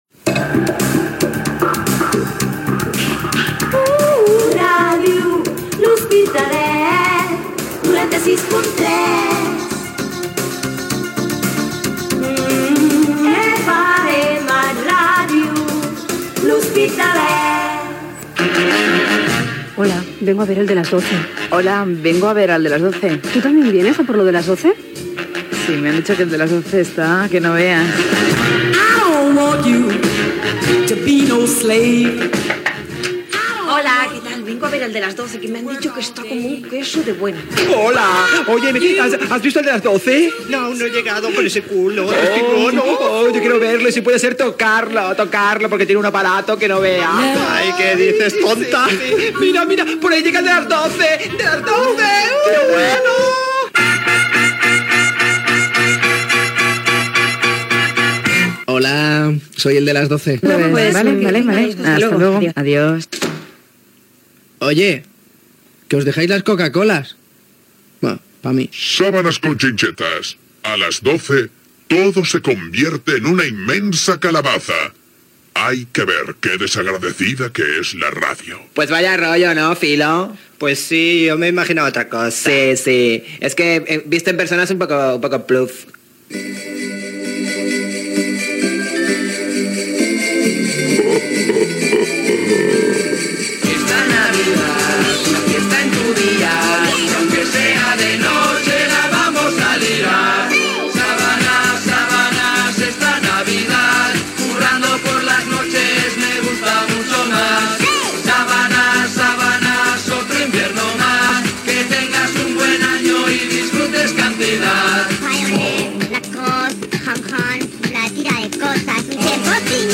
Indicatiu de la ràdio, careta del programa, cançó nadalenca del programa, presentació del programa
Entreteniment
Per distorsionar la veu i canviar el to feia servir una unitat d'efectes Yamaha SPX-90 II.